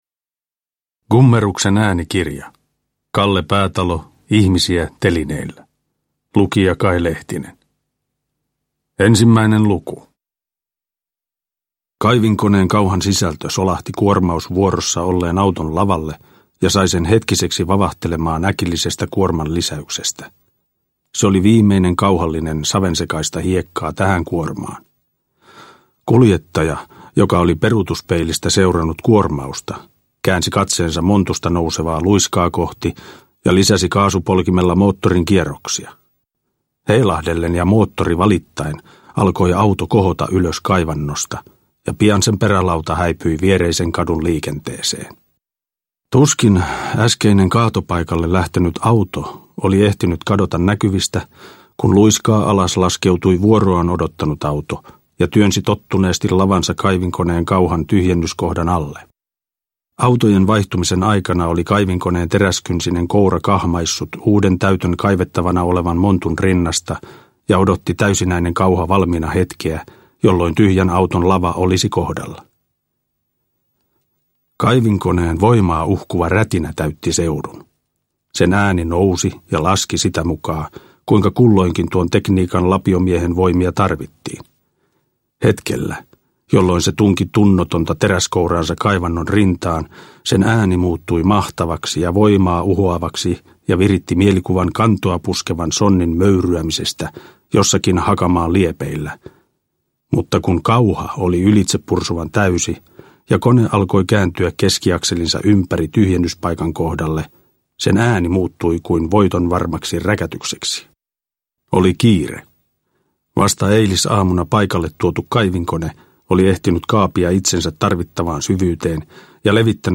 Ihmisiä telineillä – Ljudbok – Laddas ner
Äänikirjan lukee ainutlaatuisella tyylillään Kai Lehtinen, joka esitti nimiroolia Kalle Päätalosta kertovasta elämäkertaelokuvassa.
Uppläsare: Kai Lehtinen